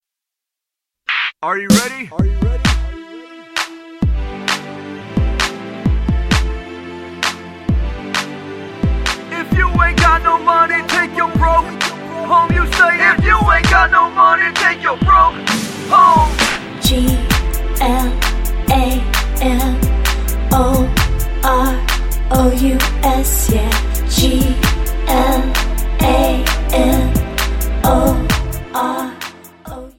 Backing track files: All (9793)
Buy With Backing Vocals.